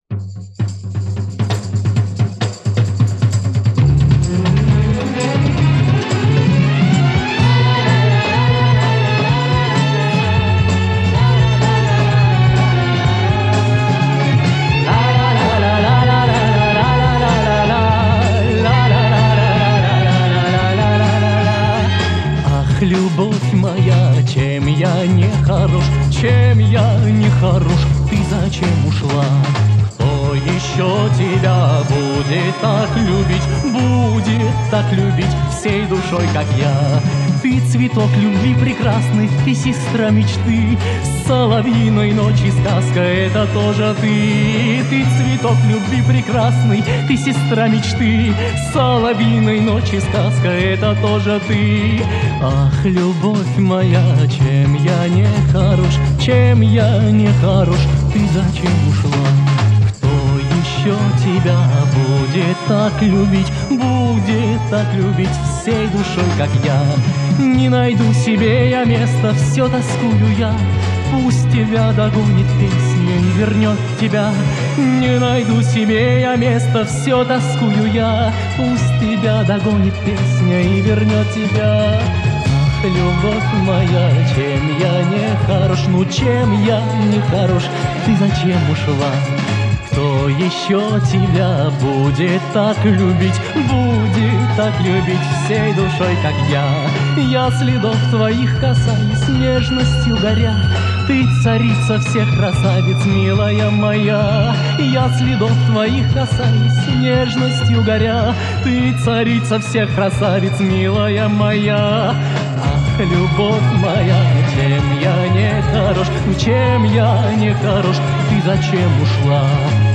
Формат - Пластинки, 7", 33 ⅓ RPM, Mono
Звук отредактирован и восстановлен